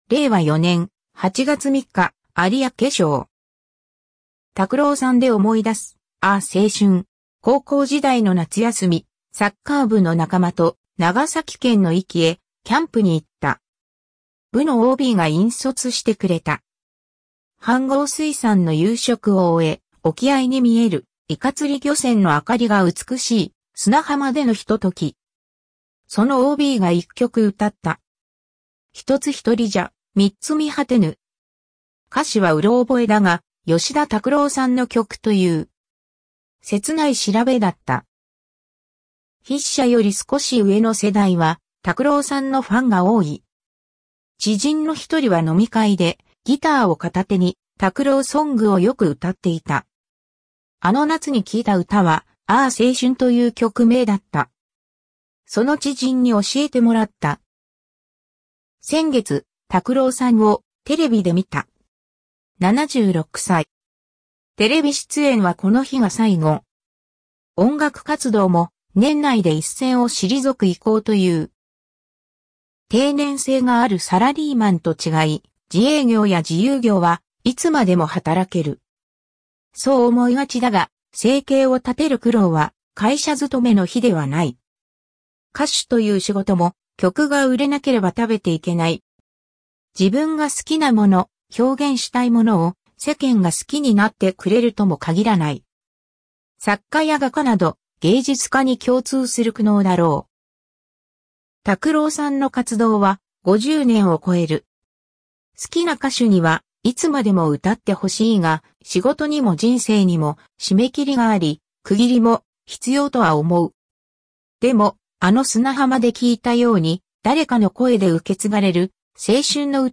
下記のボタンを押すと、AIが読み上げる有明抄を聞くことができます。